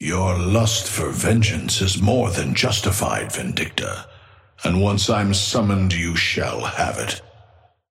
Amber Hand voice line - Your lust for vengeance is more than justified, Vindicta. And once I'm summoned, you shall have it.
Patron_male_ally_hornet_start_01.mp3